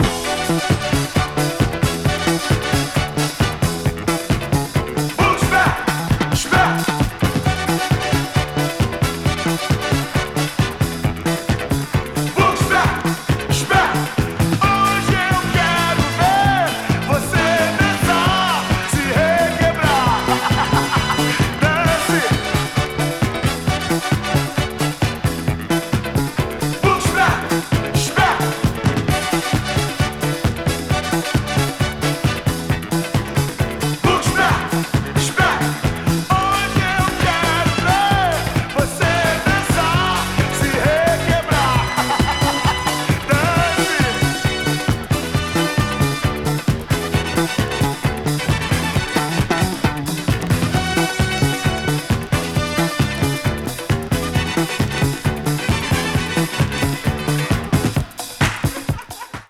B-5)など、ブラジリアン・ディスコが最高です！